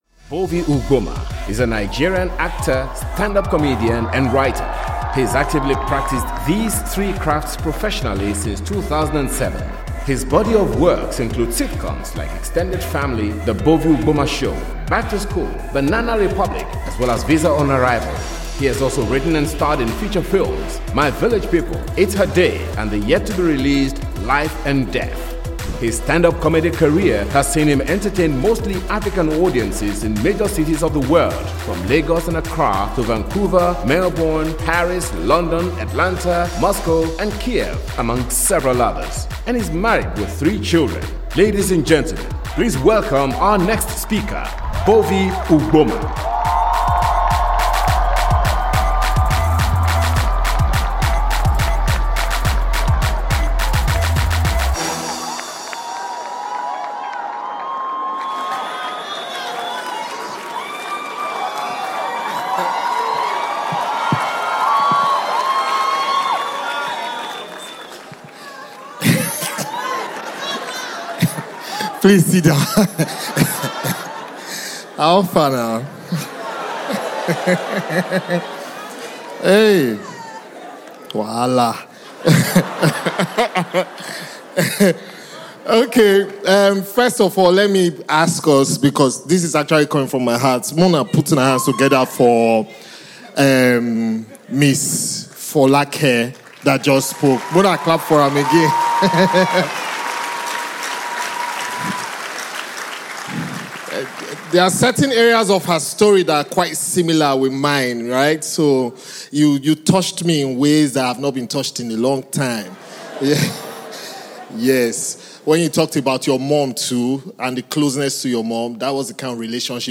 The Excellence in Leadership Conference (ELC), hosted by Daystar Christian Centre, is set to hold from Thursday, November 2-3, 2023.